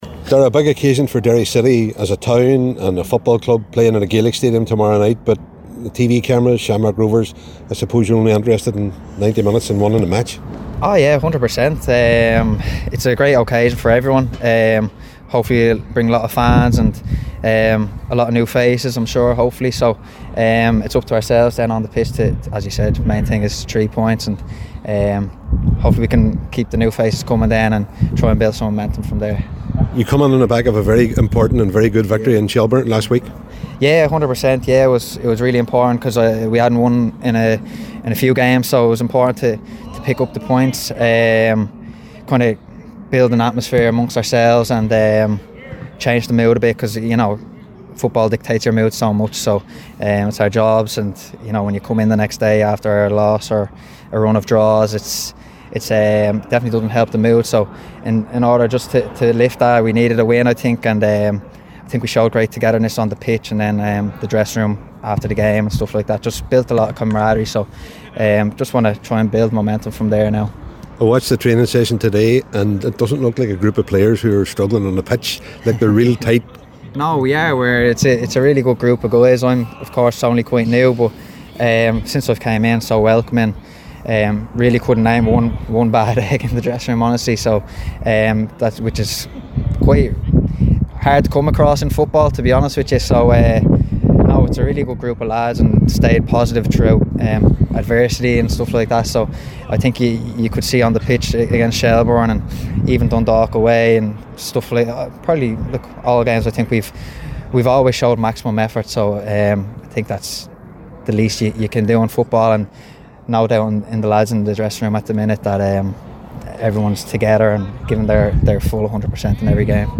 a press event in the lead up to the game